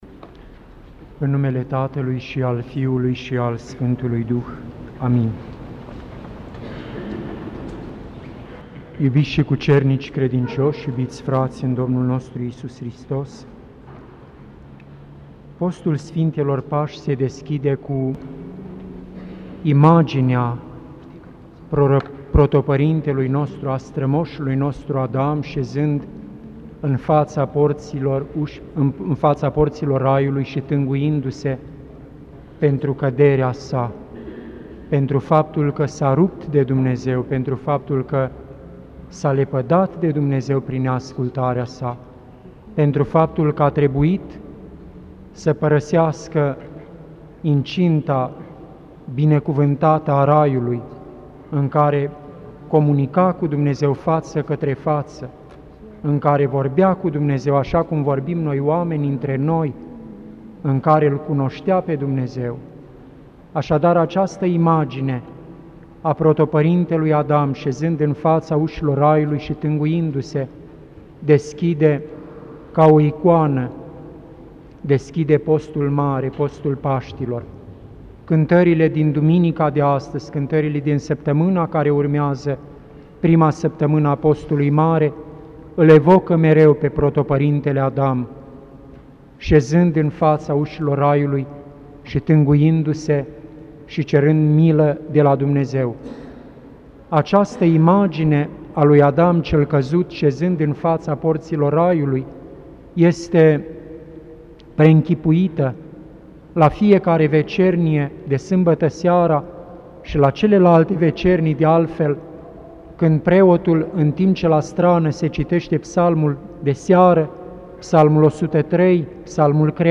Predică la Duminica Izgonirii lui Adam din Rai